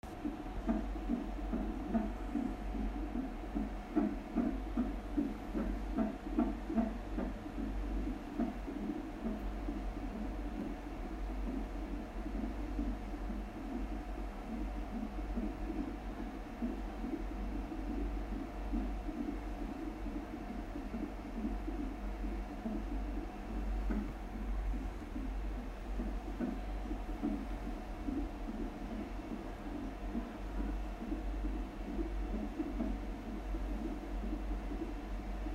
heart beat – ساند پیک آرت